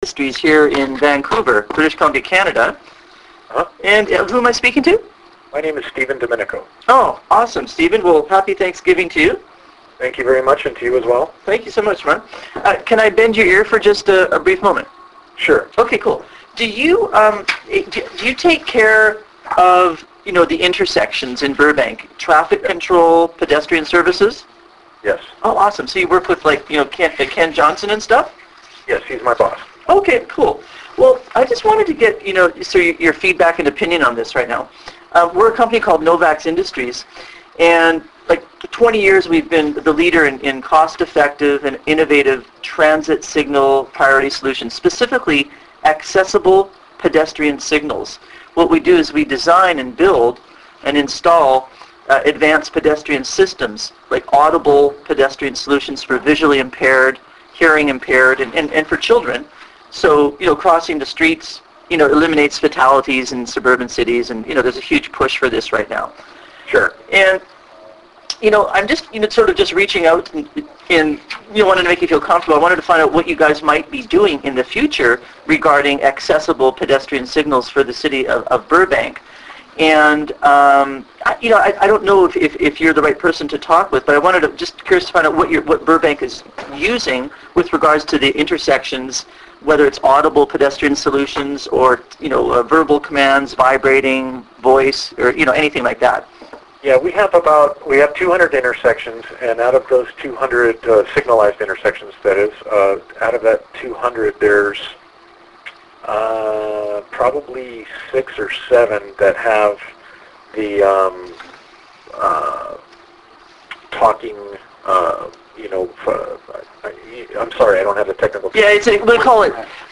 Here we are cold calling for the 1st time with the City of Burbank in California .
Posted in Appointment Setting Tactics, LIVE B2B COLD CALLS, Uncategorized | No Comments »